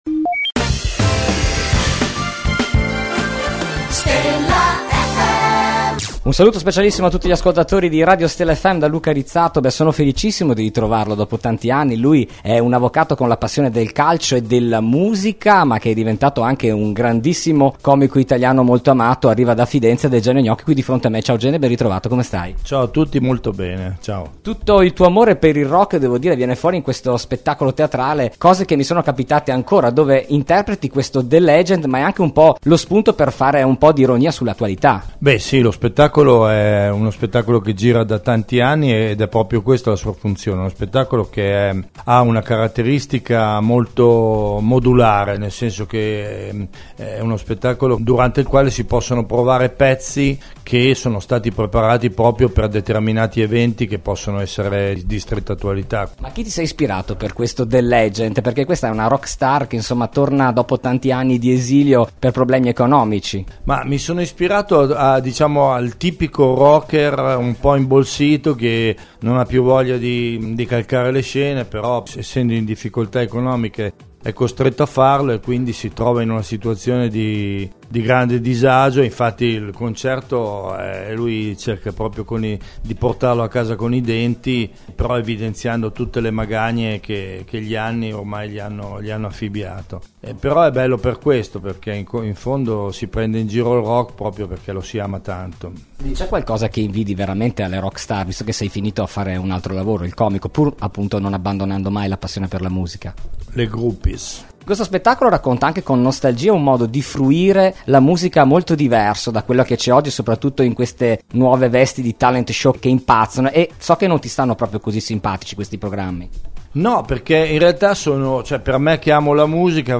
Intervista Gene Gnocchi | Stella FM